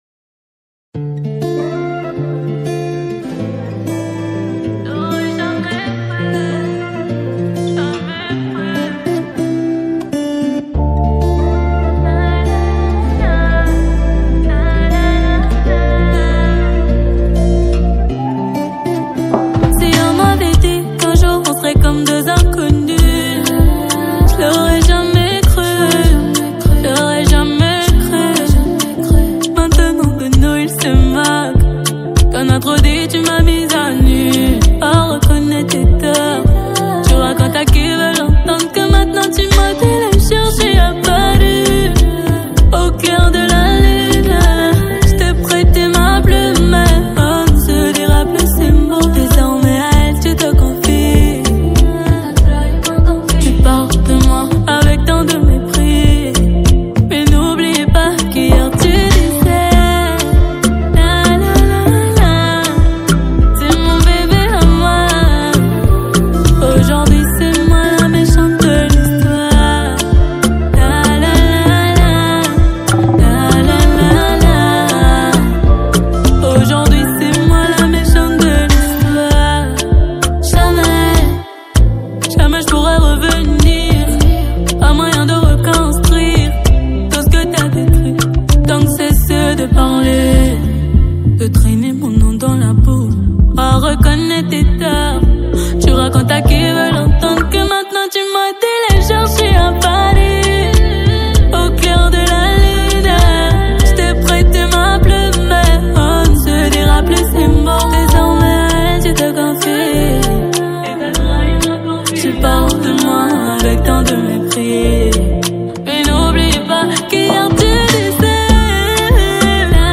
| Afro zouk